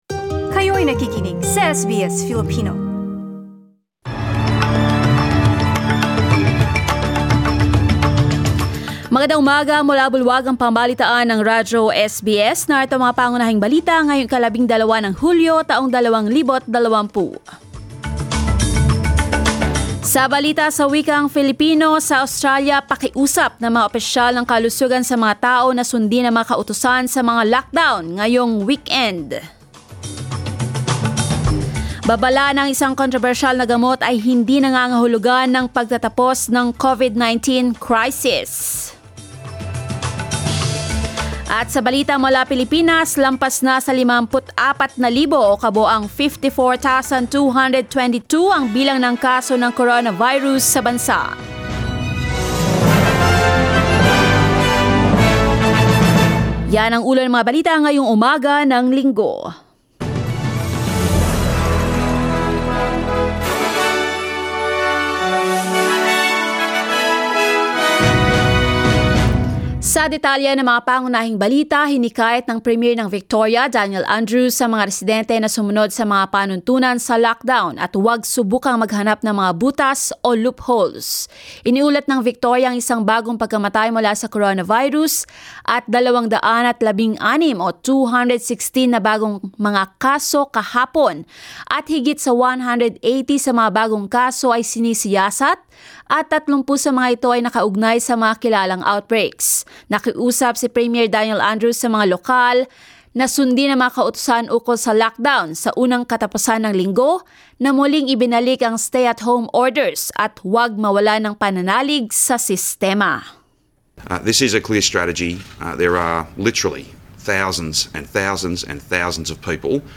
SBS News in Filipino, Sunday 12 July